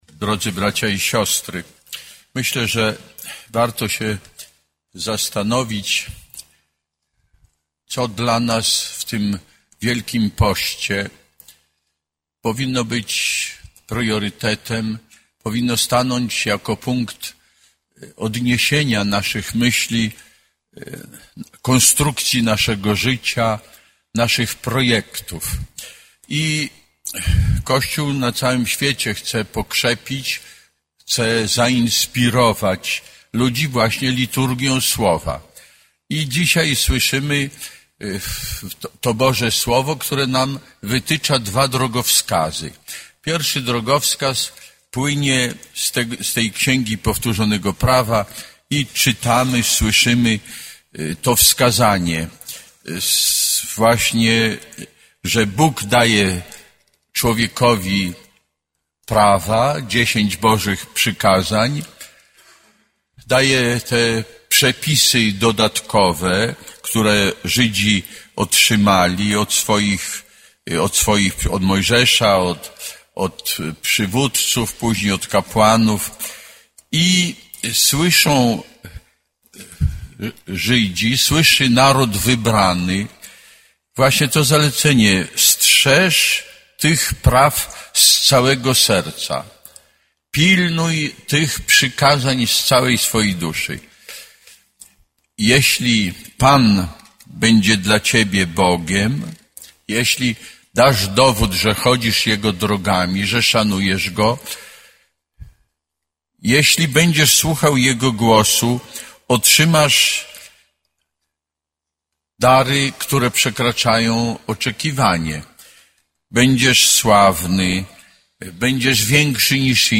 Homilia Ks. Abp. Józefa Michalika